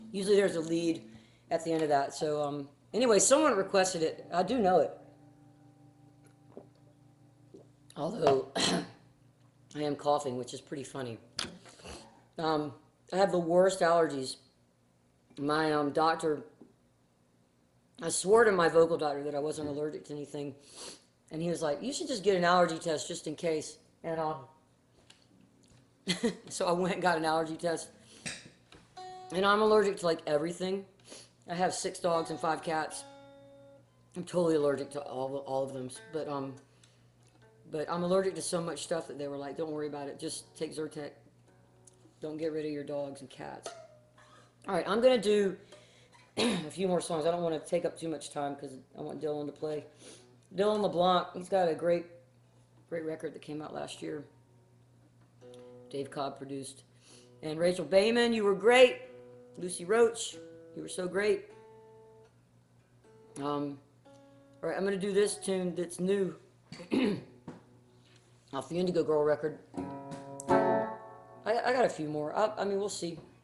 (captured from the live video stream)
12. talking with the crowd (1:13)